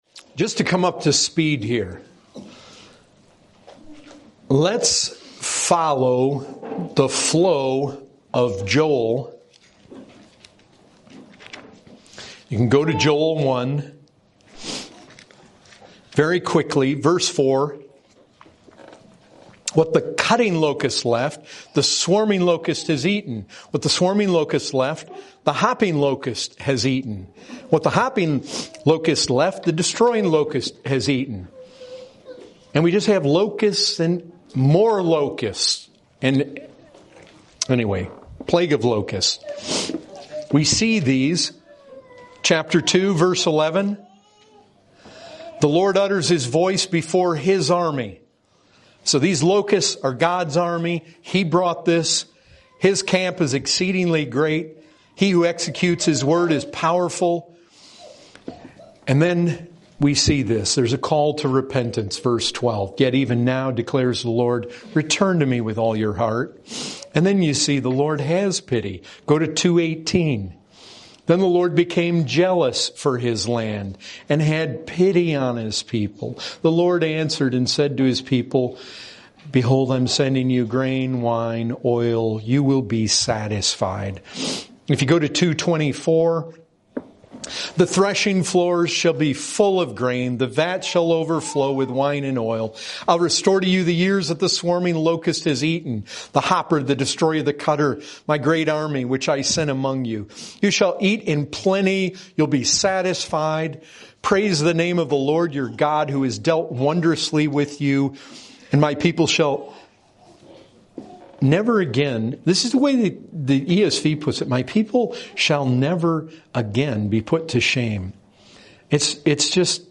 2022 Category: Full Sermons Topic